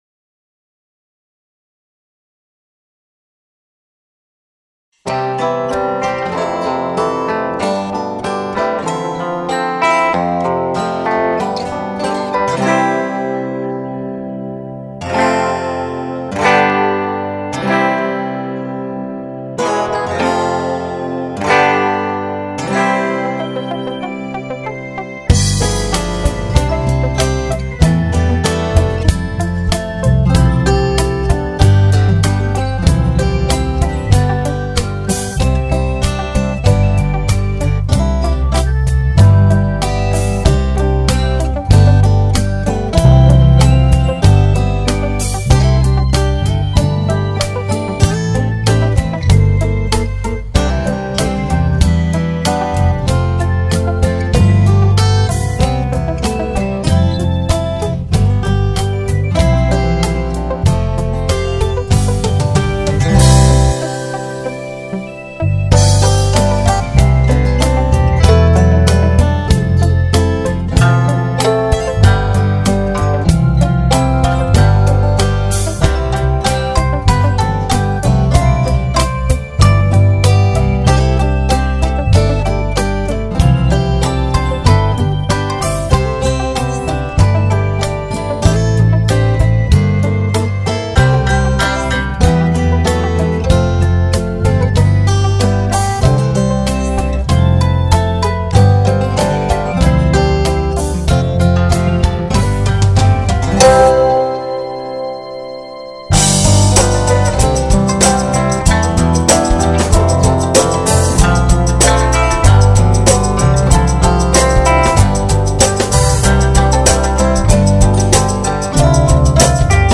mp3,5117k] Фанк
Фантазия на тему....